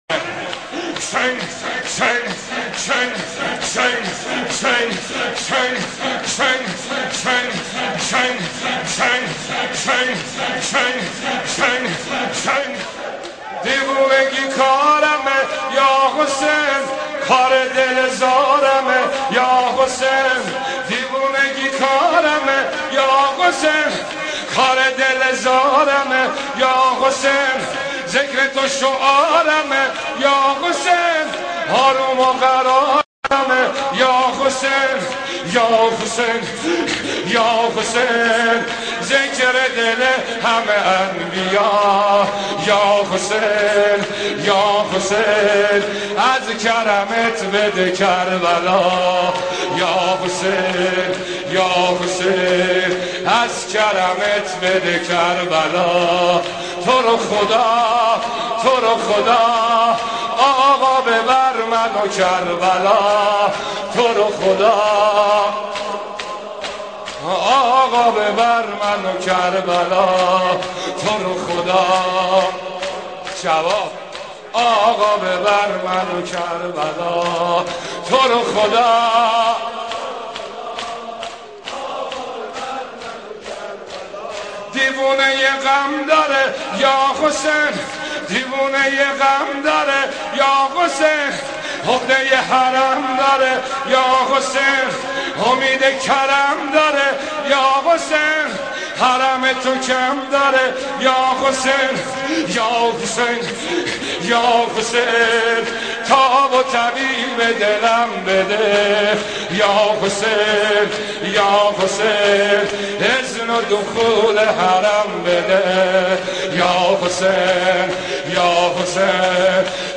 اشعار شور شب ششم محرم